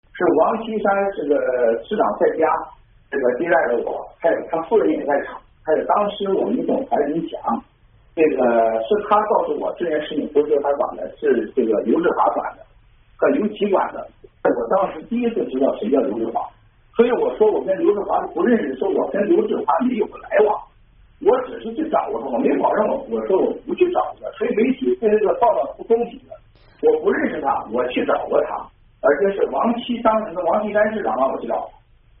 郭文贵接受美国之音采访音频Part3